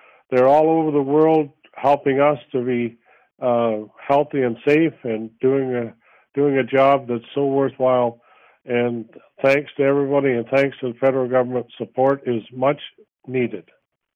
Jim Harrison tells Quinte News the funding, announced in Thursday’s federal budget, is necessary to improve military equipment, training and more at CFB Trenton and beyond.